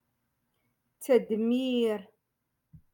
Moroccan Dialect- Rotation Five-Lesson Sixty Four